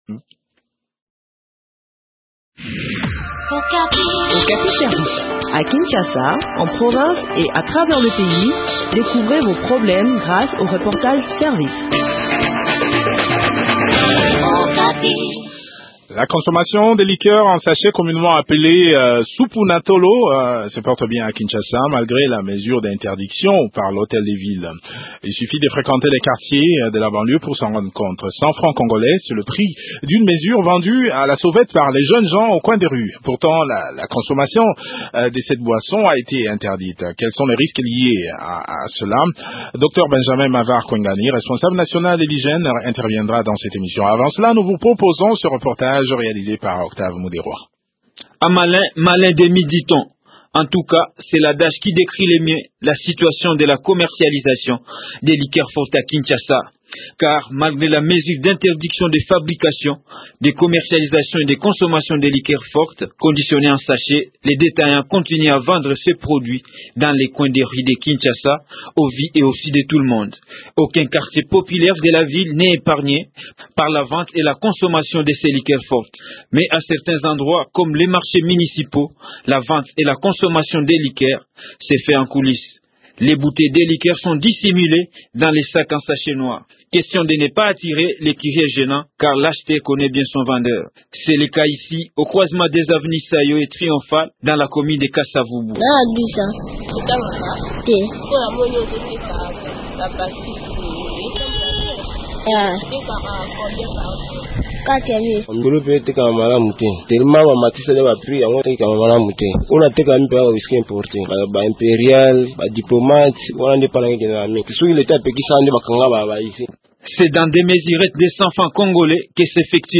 expert en santé publique répond au micro